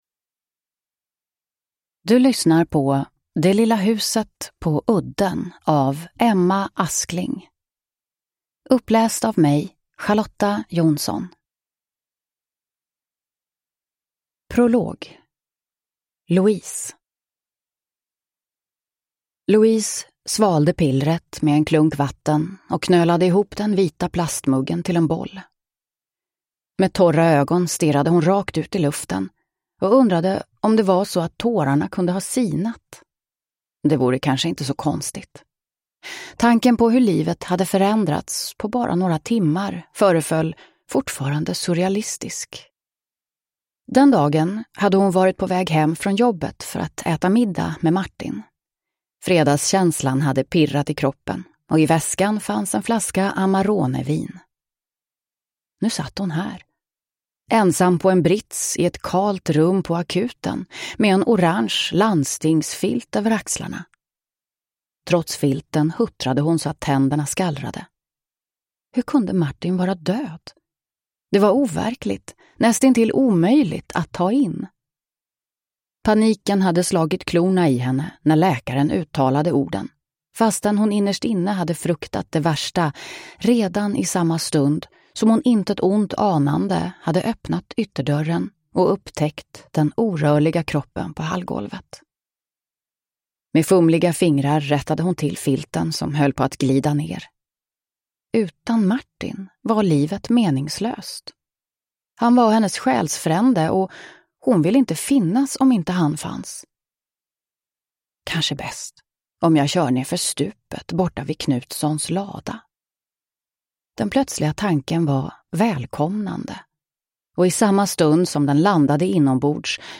Det lilla huset på udden (ljudbok) av Emma Askling | Bokon